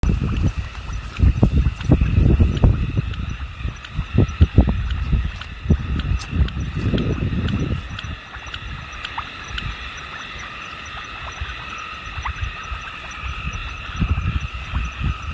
Wide-Range Fungoid Frog Scientific Name: Hydrophylax Bahuvistara